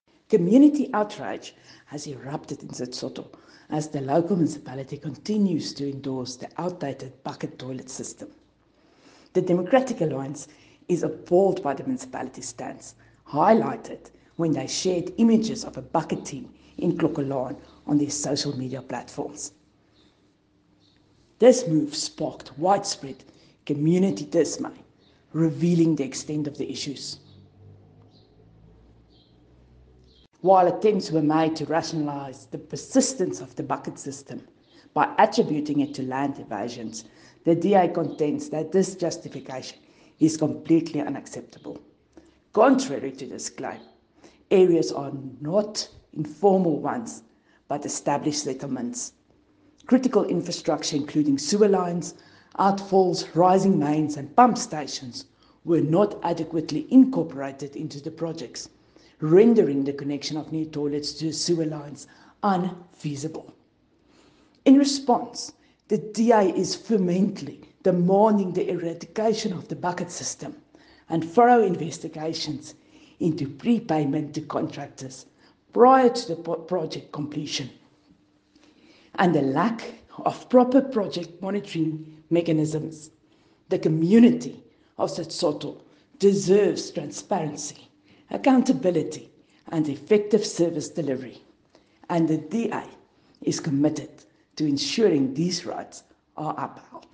Afrikaans soundbites by Cllr Riette Dell.